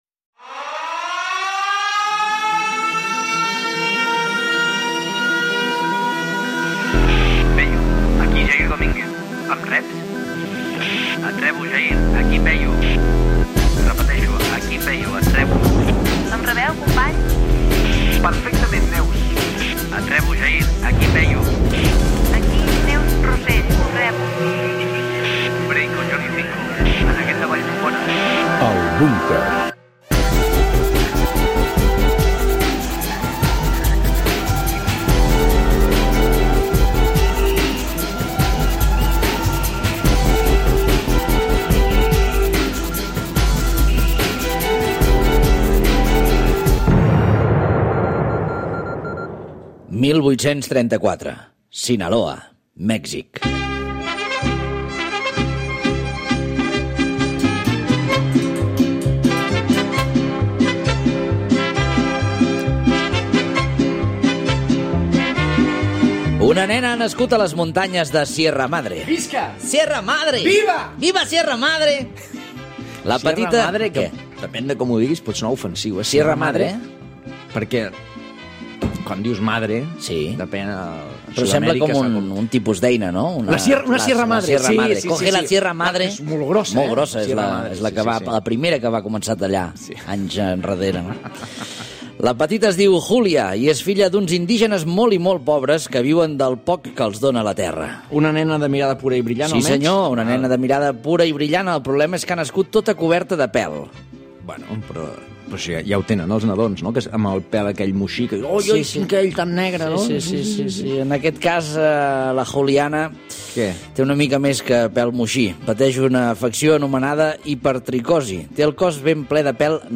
85c7abcfb6a5f30e5fd165ff54fa6bb0ad37fcec.mp3 Títol Catalunya Ràdio Emissora Catalunya Ràdio Cadena Catalunya Ràdio Titularitat Pública nacional Nom programa El búnquer Descripció Careta del programa, El cas de Julia Pastrana, nascuda el 1834 a Mèxic. Una oïdora explica una anècdota personal.
Les curses de formatges en un poble anglès Gènere radiofònic Entreteniment